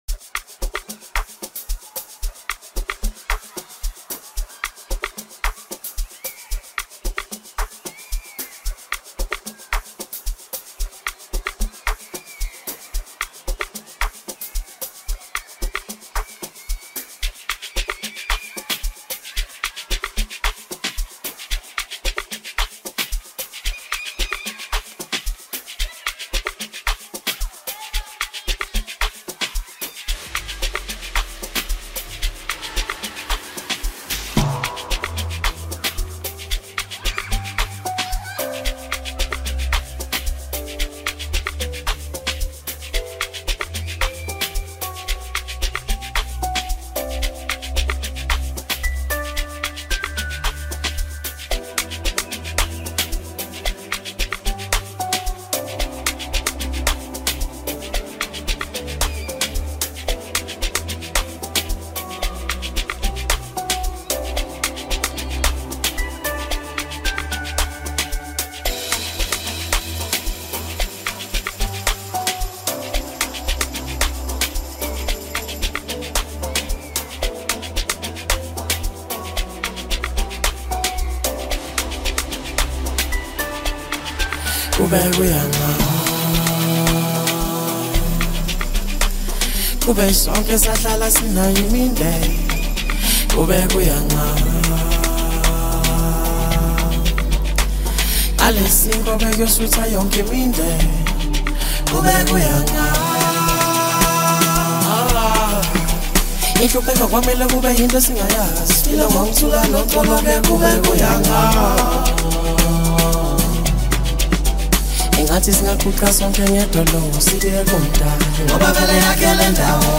Home » Amapiano » DJ Mix » Lekompo